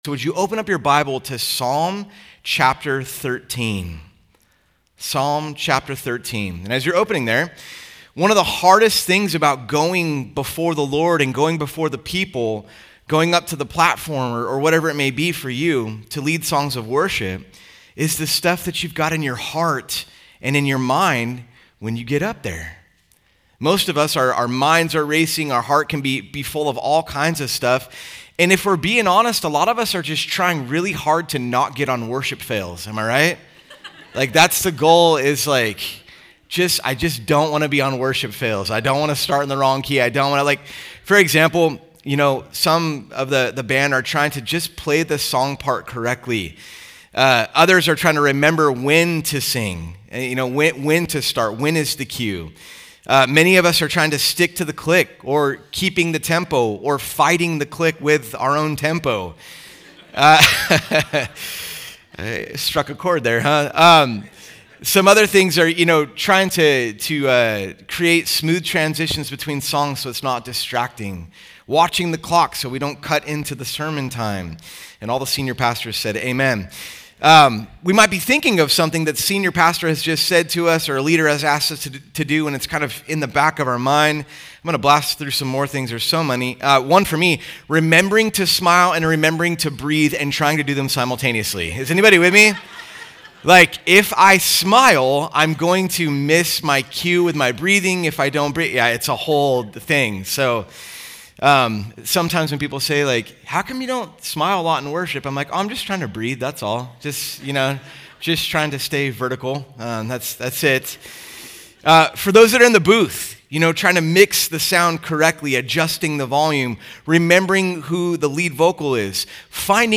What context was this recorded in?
Conference: Worship Conference